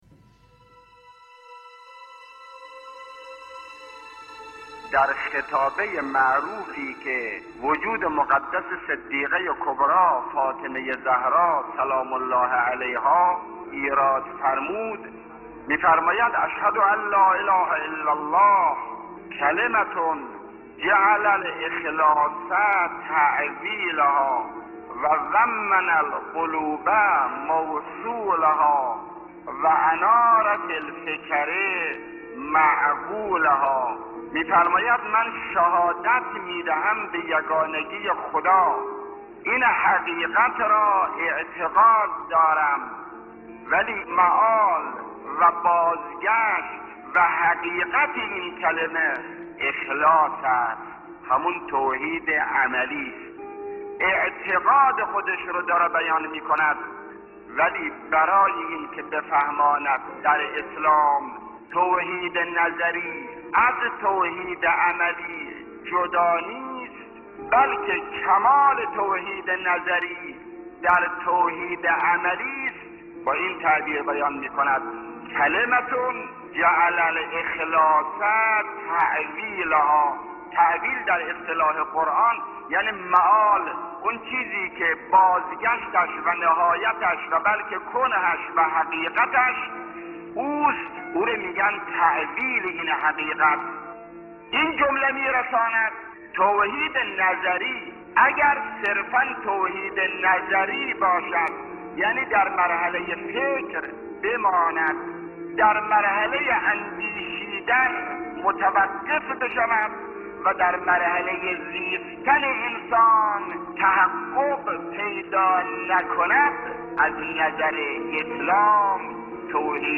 مطهری
سخنرانی